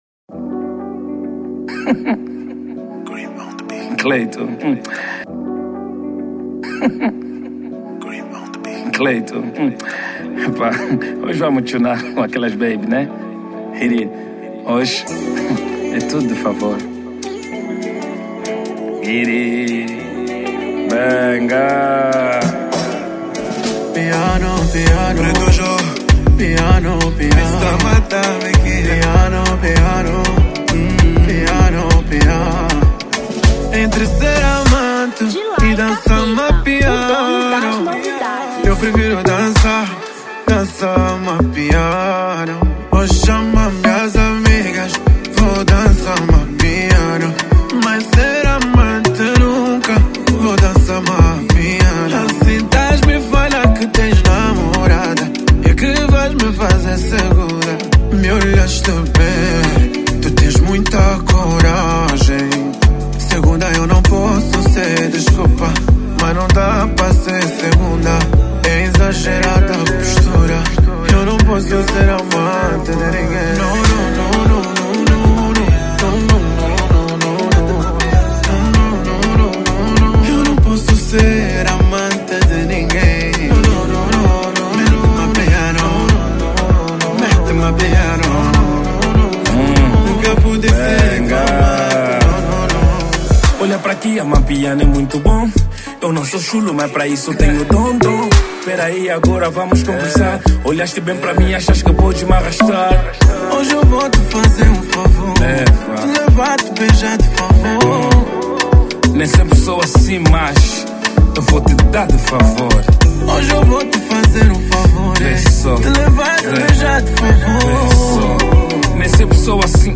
Afro Pop 2025